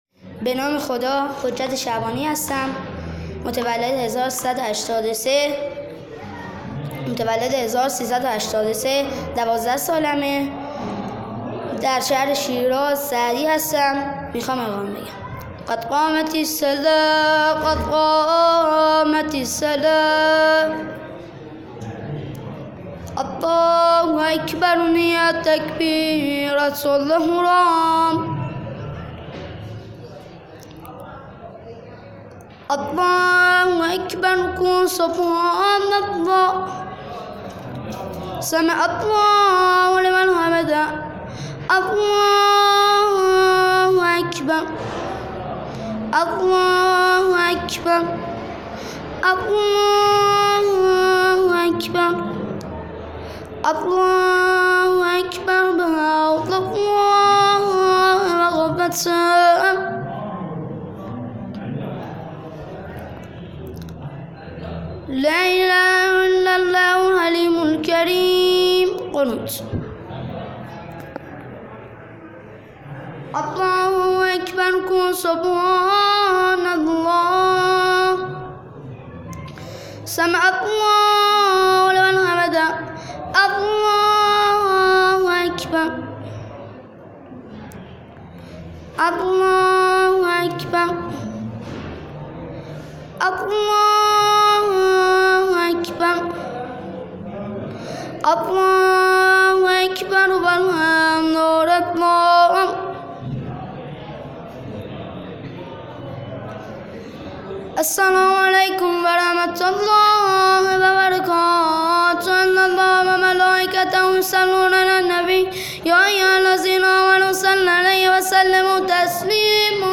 فایل صوتی اقامه